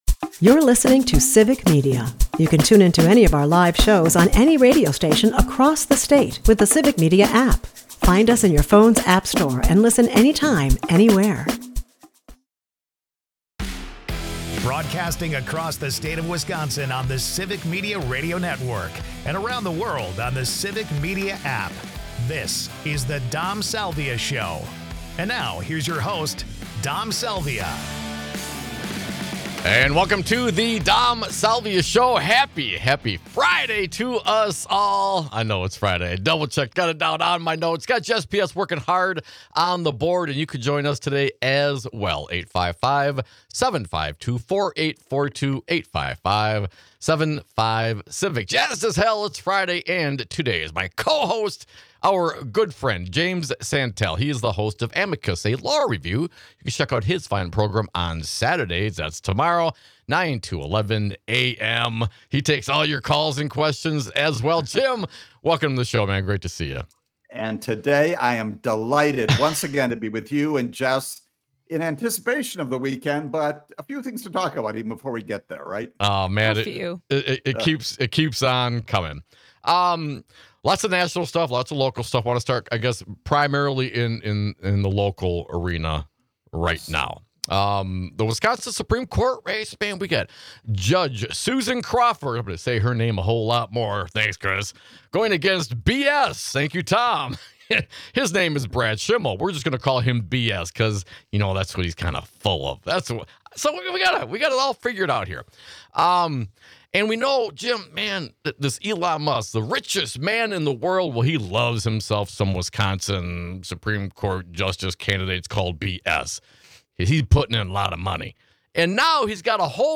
news (some), humor (more), and great conversation (always)!